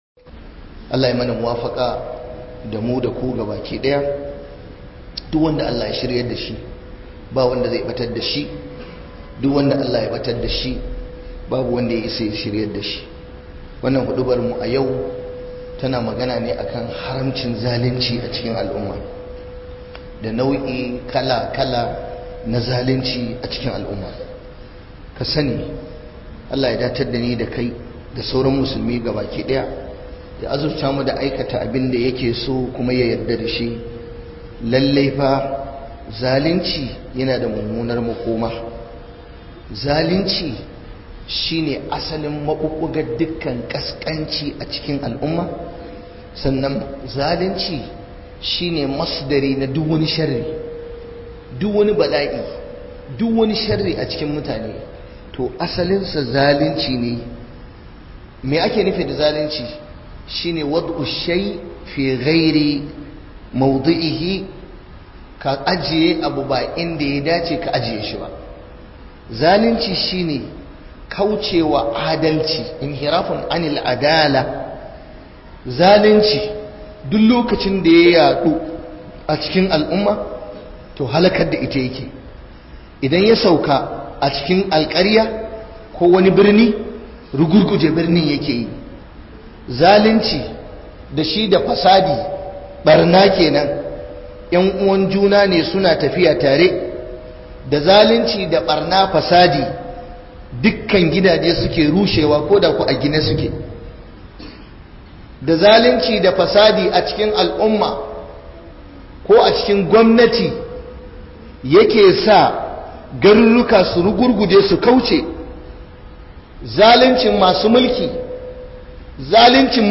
'YAN UWANTAKA - Huduba